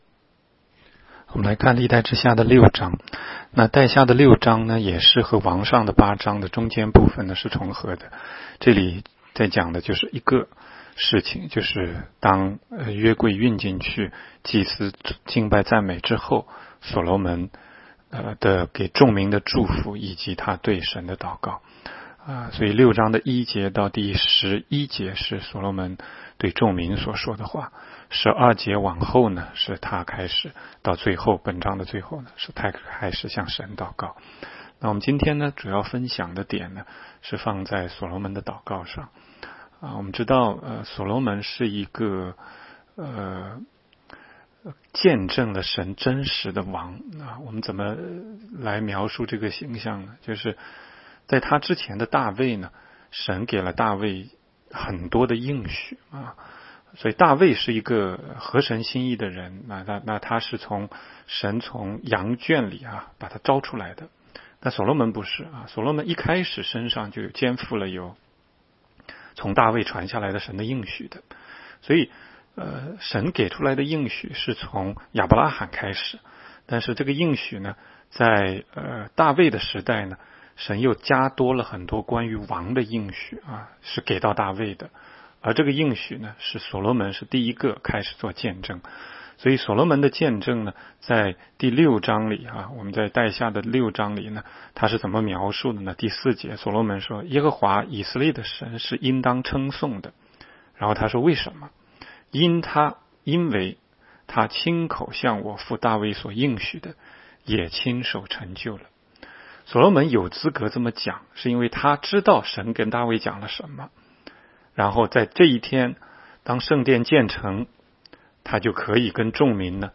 16街讲道录音 - 每日读经-《历代志下》6章